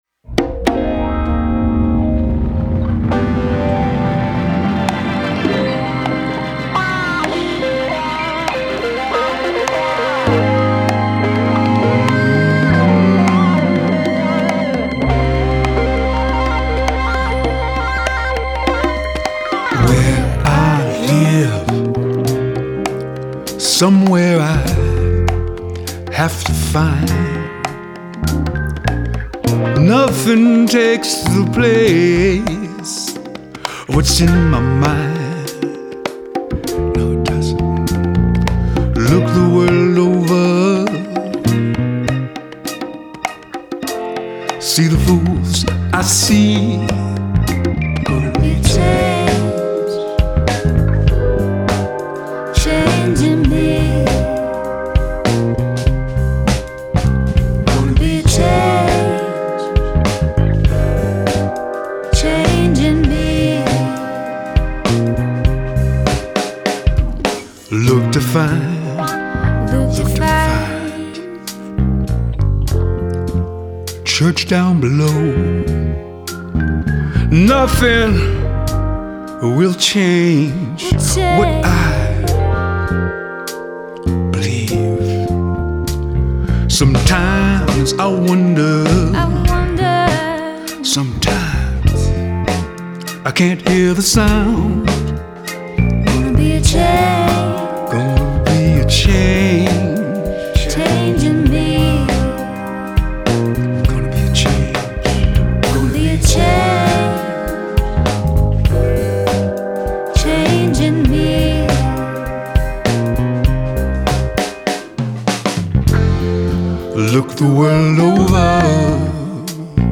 Genre : Rock, Blues